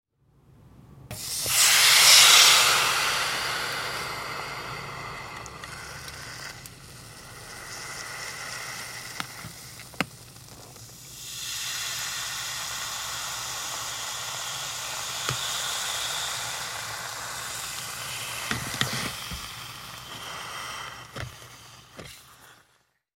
Звуки утюга
Шипение раскаленного утюга